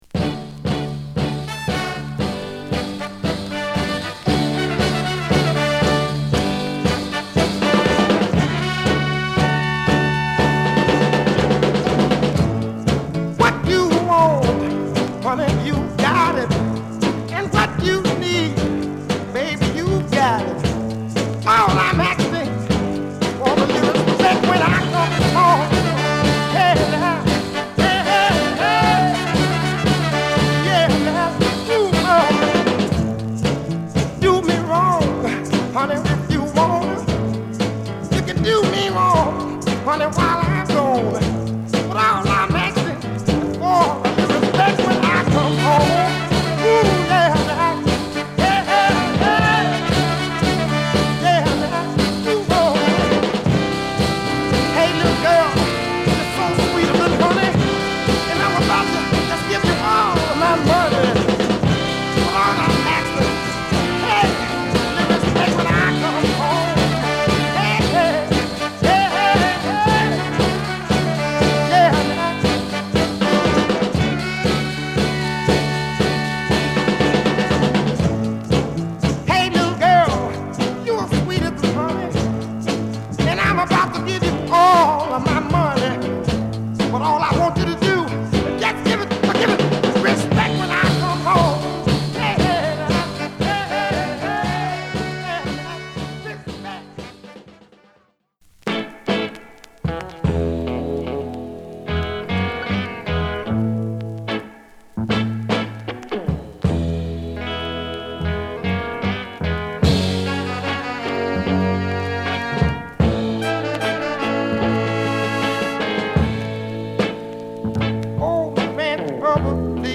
7inch